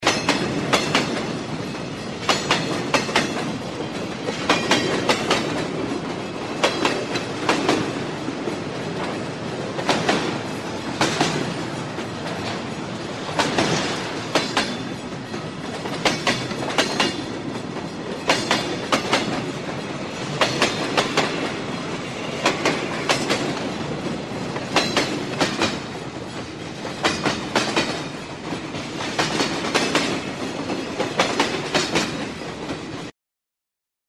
Скрип железных рельсов под движущимся поездом